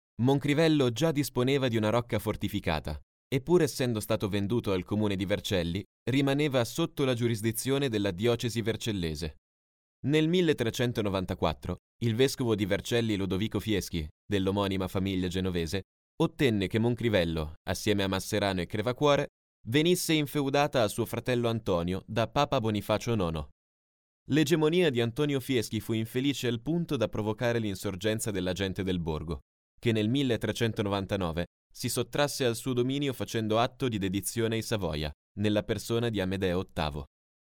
Male
Confident, Engaging, Friendly, Natural, Versatile, Corporate, Deep, Young
Microphone: SE Electronics 2200A / Shure MV7